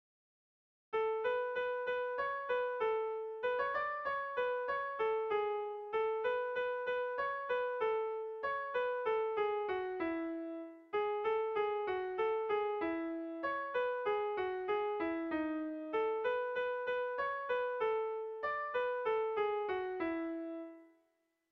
Tragikoa
Zortziko txikia (hg) / Lau puntuko txikia (ip)
A1A2BA2